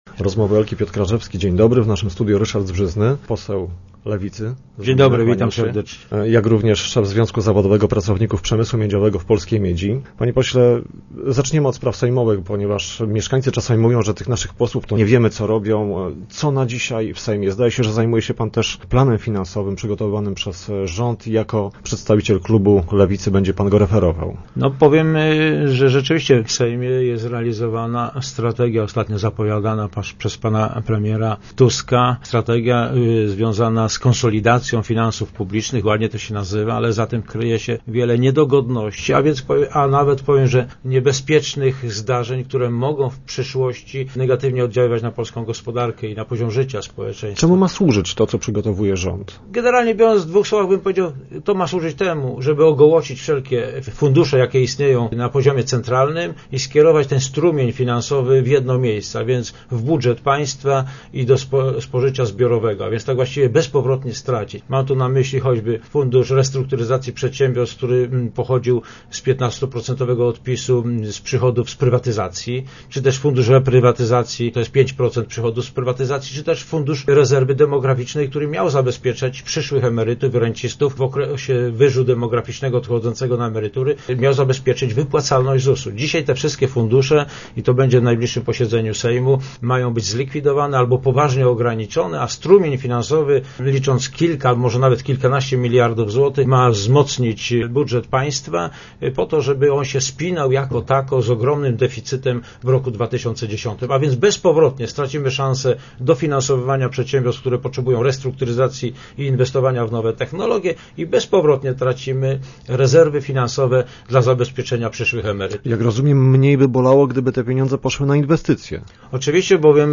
Czy Lubin znajdzie się na skraju odkrywkowej dziury? Poseł lewicy Ryszard Zbrzyzny był gościem piątkowych Rozmów Elki.
Ryszard Zbrzyzny w naszym studiu odniósł się do rządowych planów zmniejszenia finansowych rezerw trzymanych na czasy kryzysu.